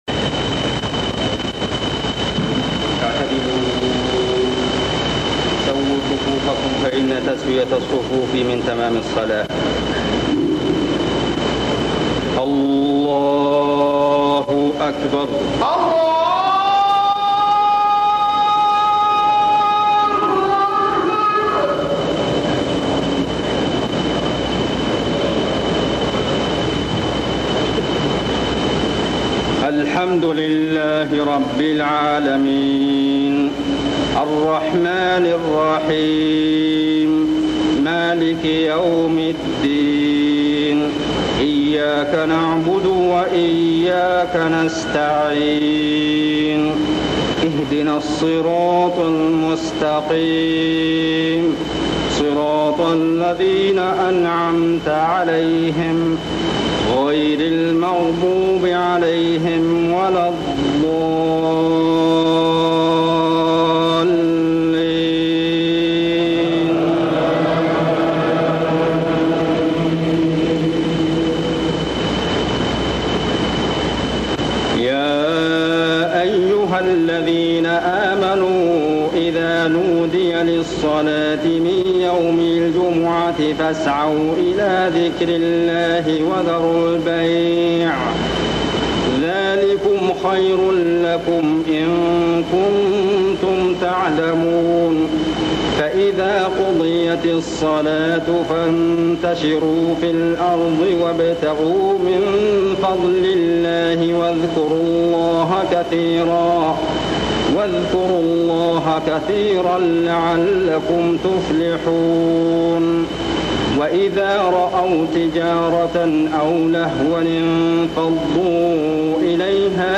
الركعه الأولى من صلاة الجمعة - آواخر سورة الجمعة - المسجد النبوي 1402هـ > 1402 🕌 > الفروض - تلاوات الحرمين